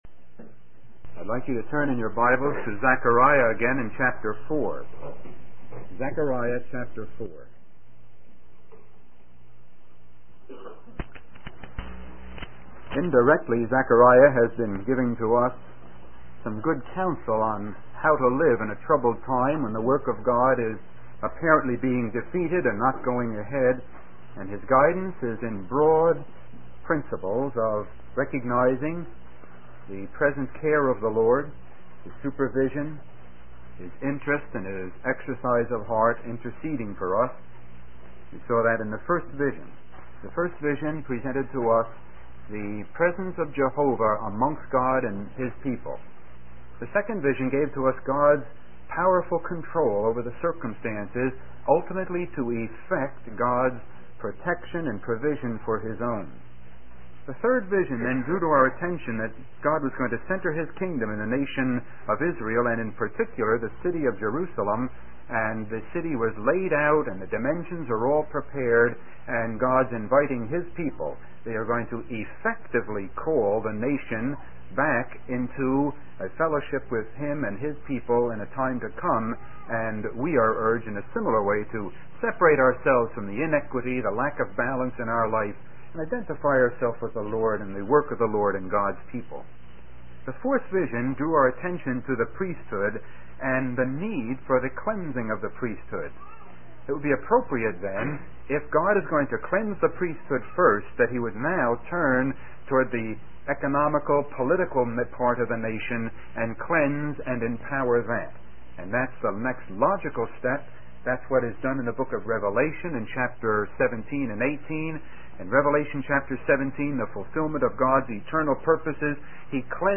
Mid South Conference 1978-11 Zechariah's Visions